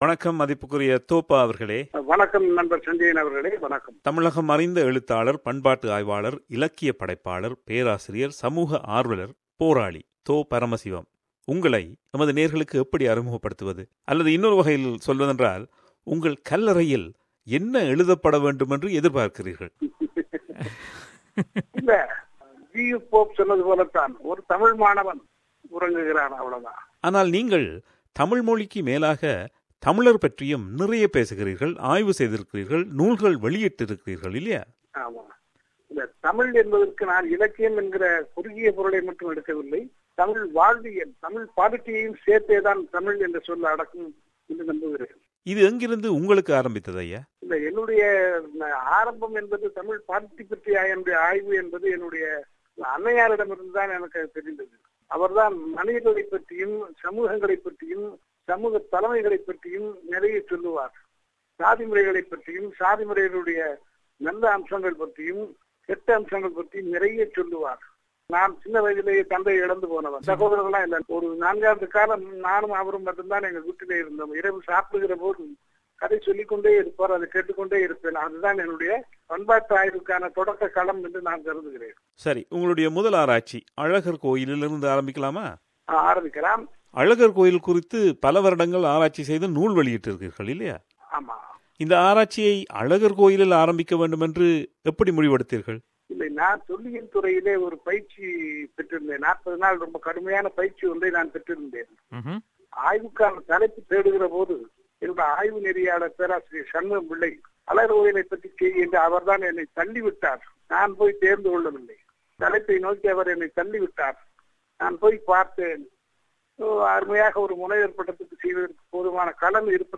அவர் கடந்த 2016ம் ஆண்டு நமக்கு வழங்கிய நேர்காணல் இது.